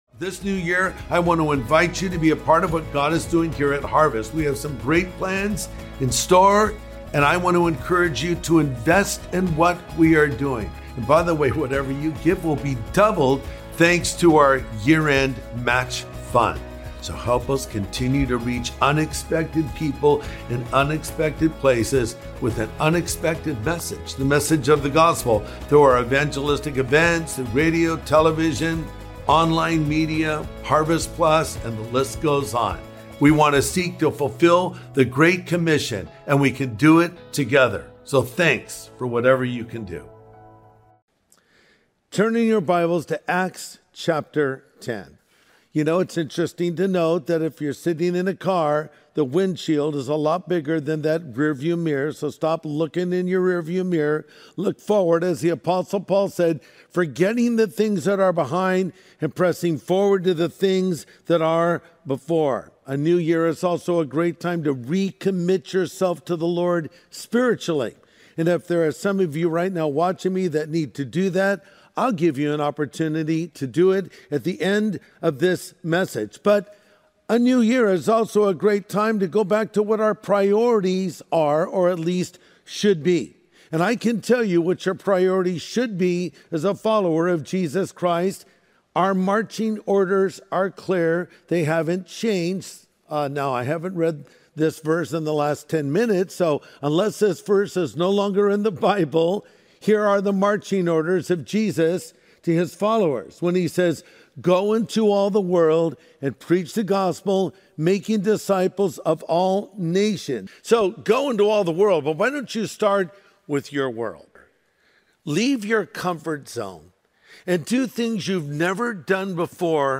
Never Say Never | Sunday Message
Pastor Greg Laurie reminds us to say to God, "Not my will, but Yours."